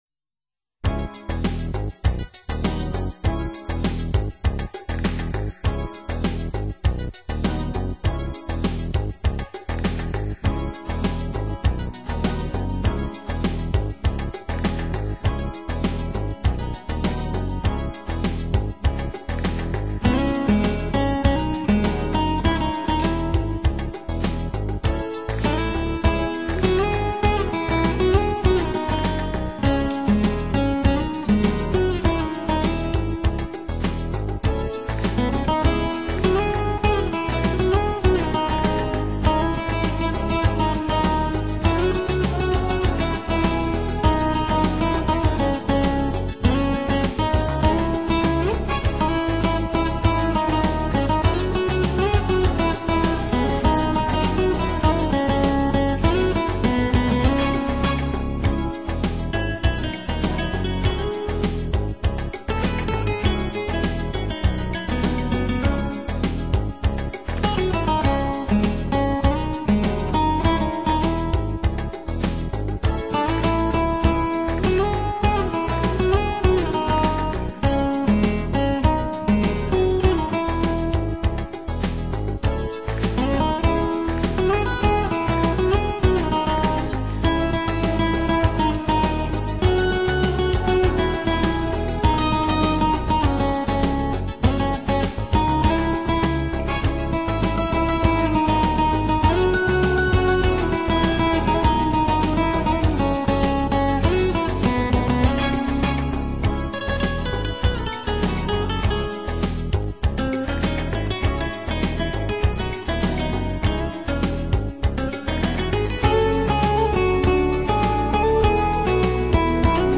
吉他跳出每一个音符都像穿透灵魂一般让人感觉温暖陶醉
纯吉他演奏，音色的空间感和细节的解析任你在挑剔的耳朵都要为之征服。
温暖、醇厚而又浓烈的吉他音色，加上顶级器材那绚丽灵魂的碰撞，灵魂的冲击铸就了天籁之音。